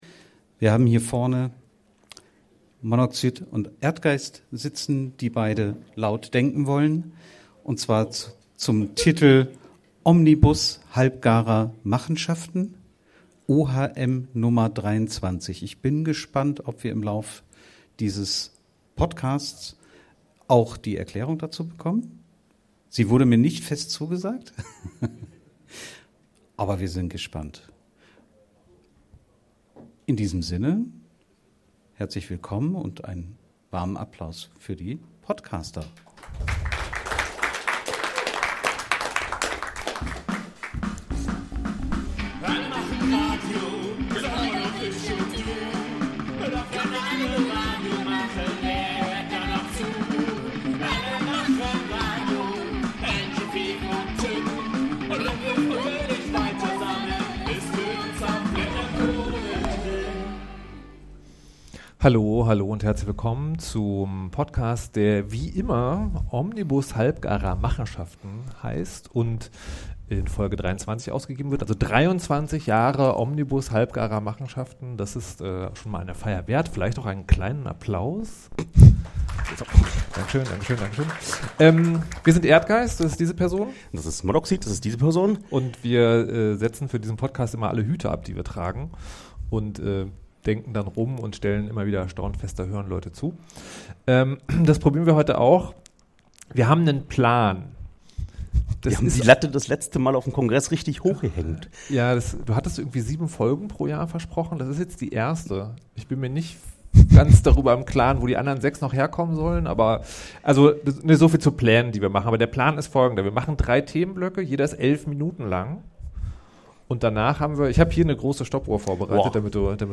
Aufgrund des großen Erfolgs soll das jetzt auch beim Congress versucht werden.